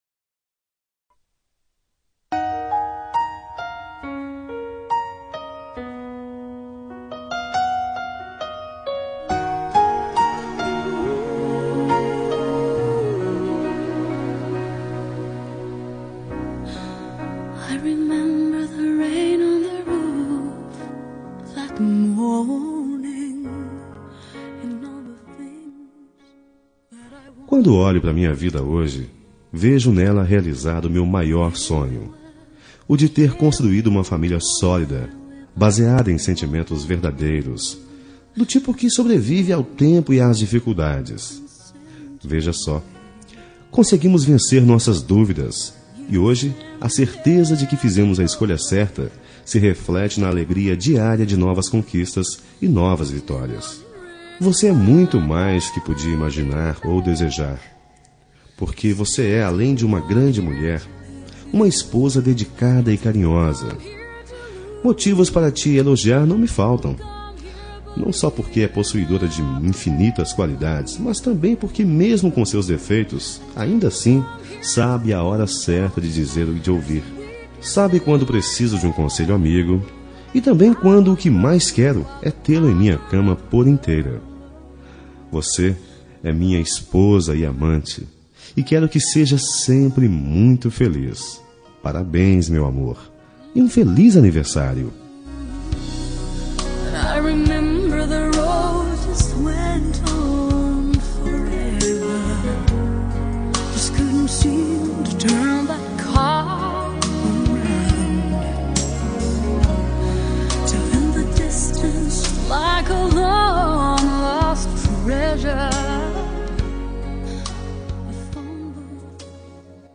Telemensagem de Aniversário de Esposa – Voz Masculina – Cód: 1121